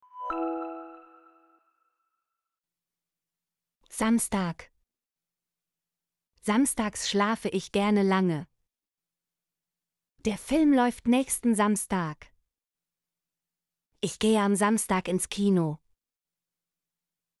samstag - Example Sentences & Pronunciation, German Frequency List